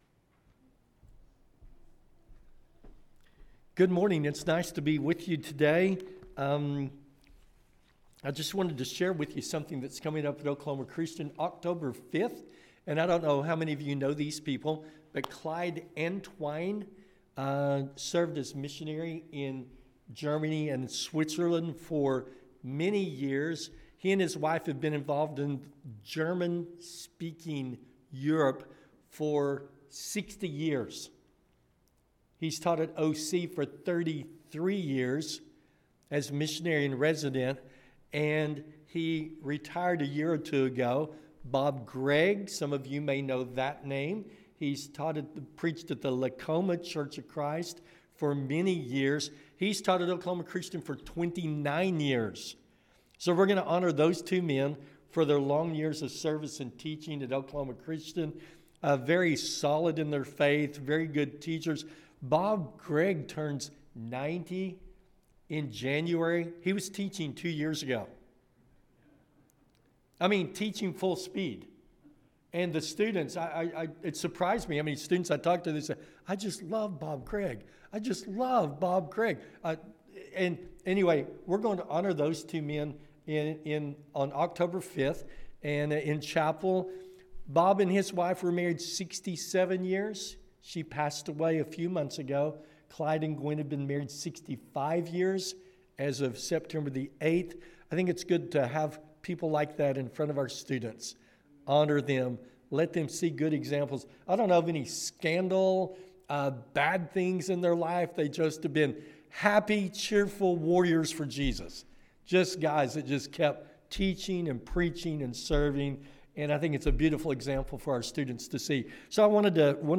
– Sermon — Midtown Church of Christ